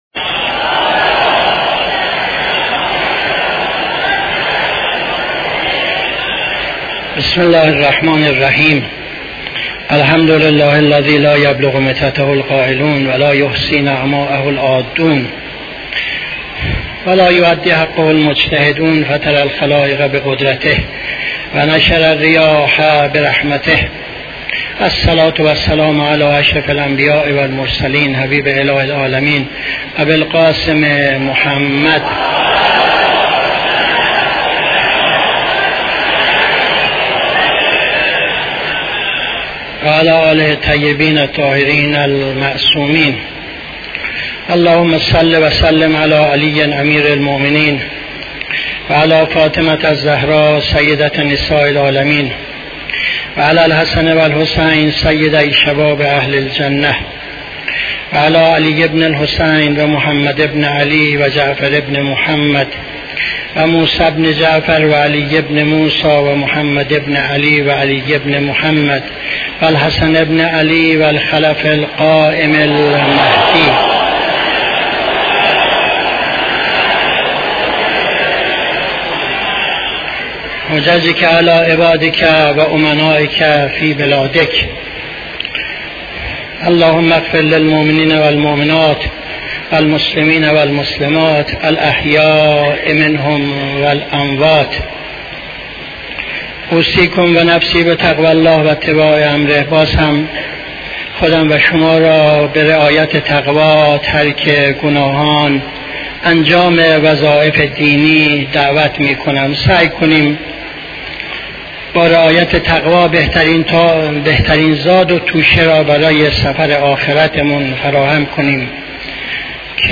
خطبه دوم نماز جمعه 20-06-77